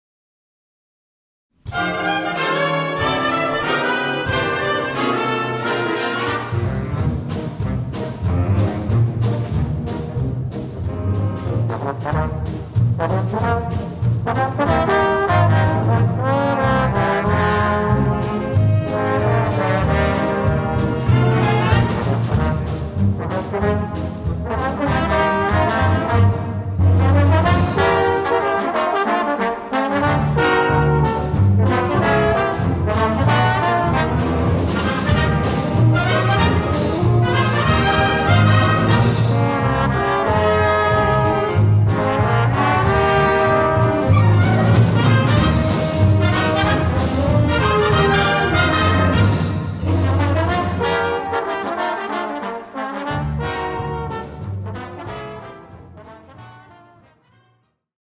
Besetzung: 4 Posaunen & Blasorchester